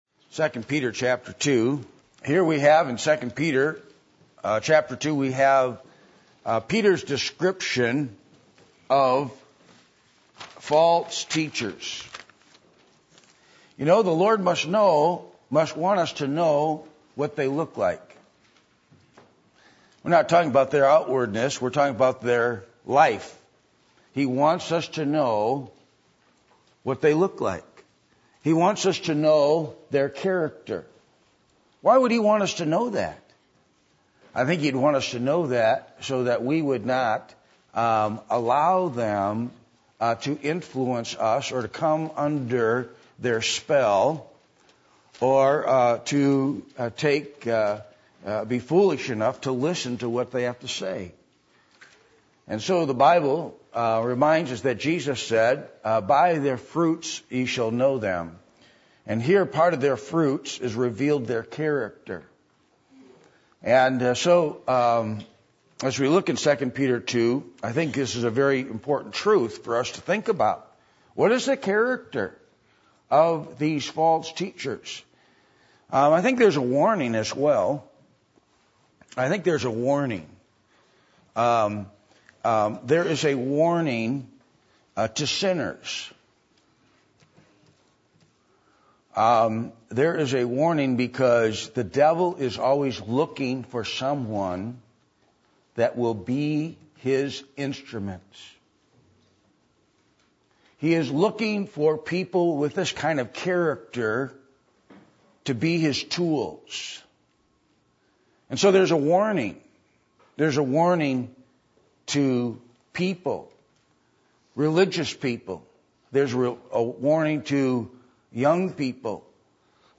Passage: 2 Peter 2:10 Service Type: Sunday Evening